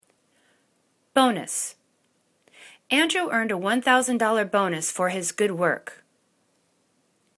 bo.nus    /'bo:nәs/   n